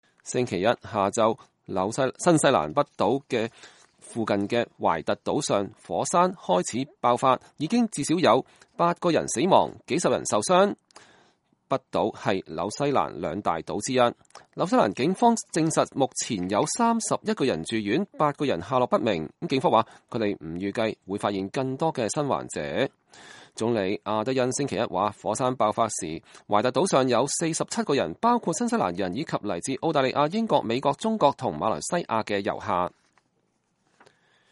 社交媒體視頻顯示新西蘭懷特島噴發的火山。(2019年12月9日)